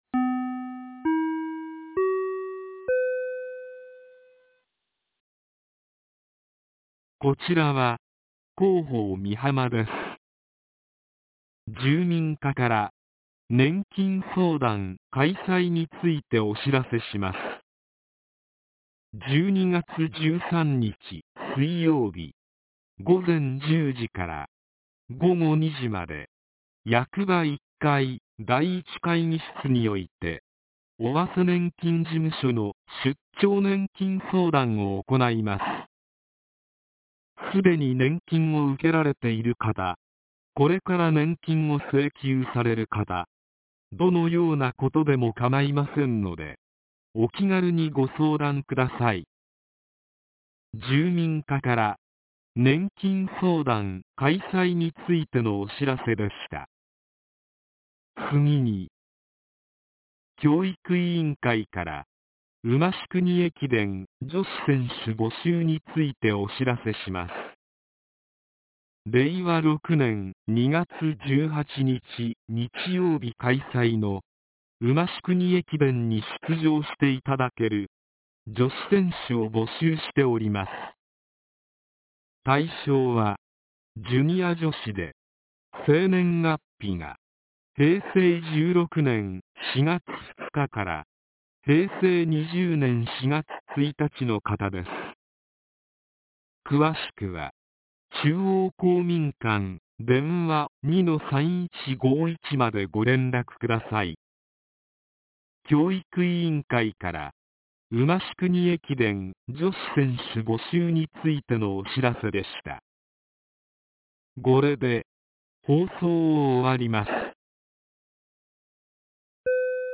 ■防災行政無線情報■
放送内容は下記の通りです。